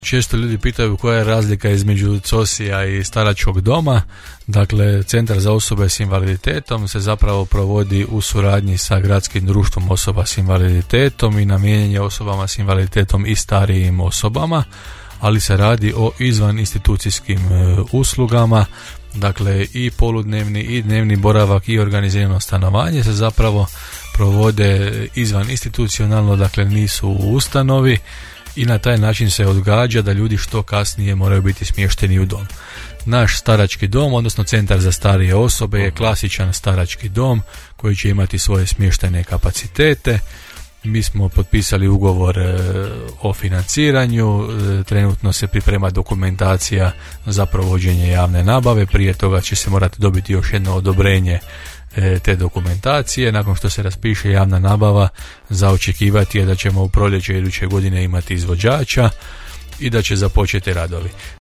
Gradonačelnik Janči u emisiji je govorio o nastavku radova na aglomeraciji te dječjem vrtiću Maslačak, gerontodomaćicama, bogatom programu Jeseni u Đurđevcu a pojasnio je i razliku Centra za socijalnu skrb i novog staračkog doma u Đurđevcu: